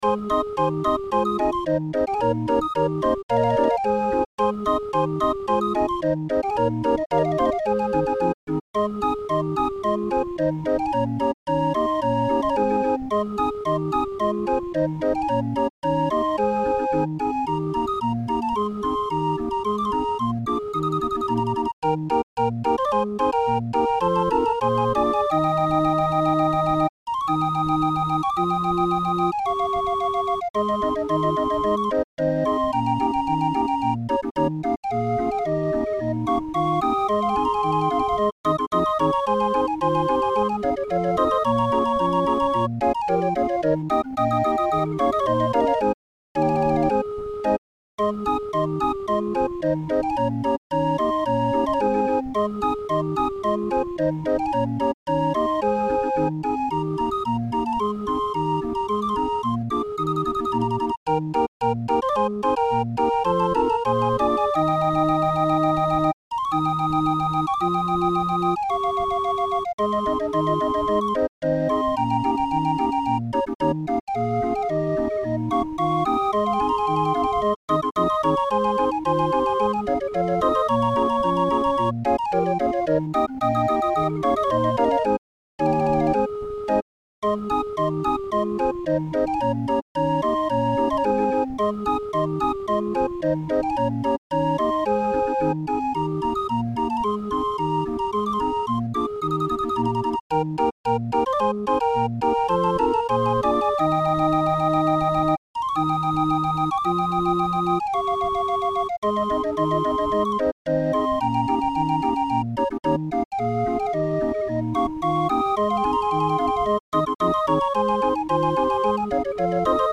Musikrolle 31-er Raffin